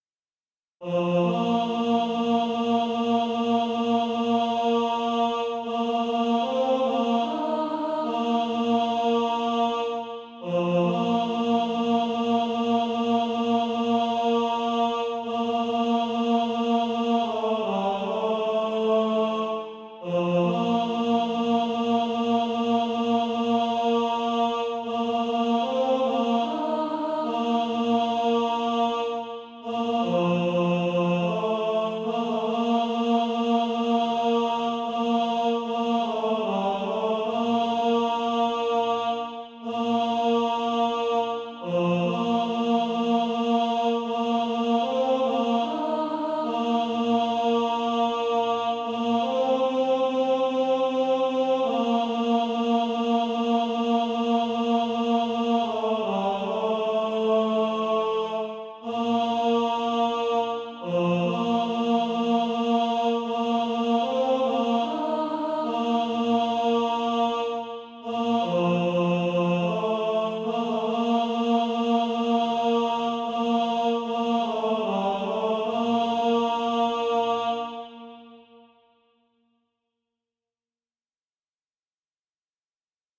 (SATB) Author
Tenor Track.
Practice then with the Chord quietly in the background.